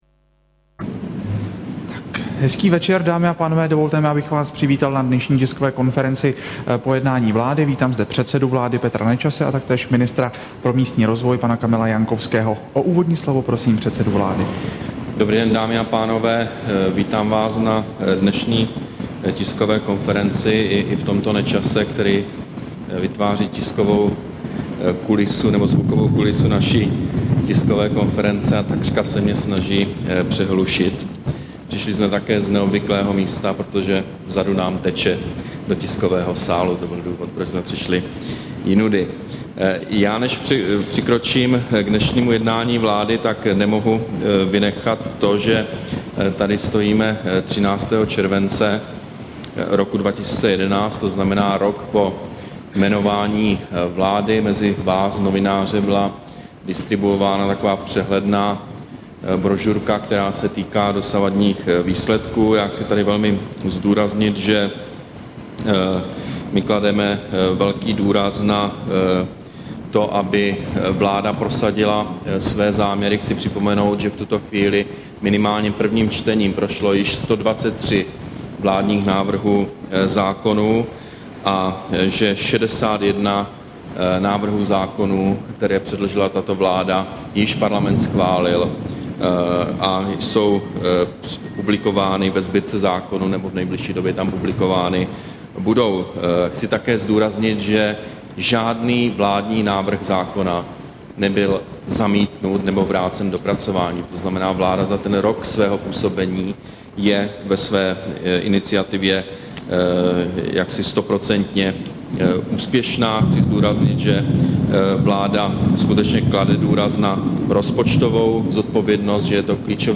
Tisková konference po jednání vlády, 13. července 2011